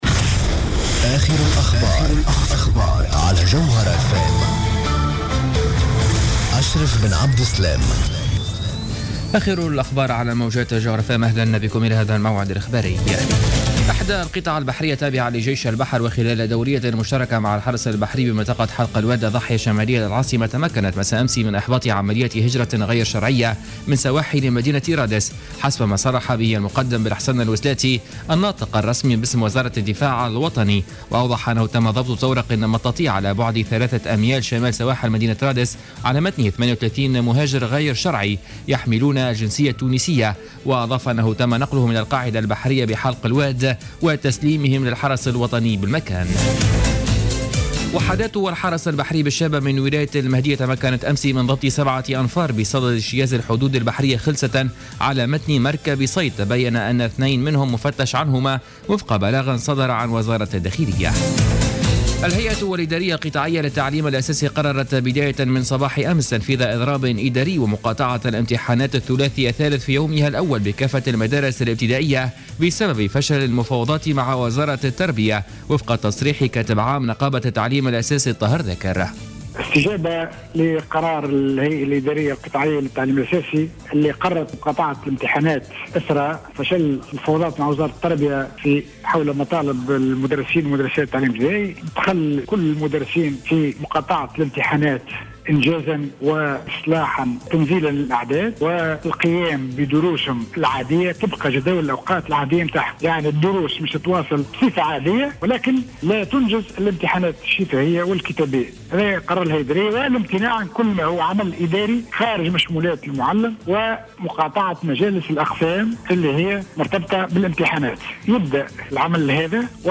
نشرة أخبار منتصف الليل ليوم الثلاثاء 02 جوان 2015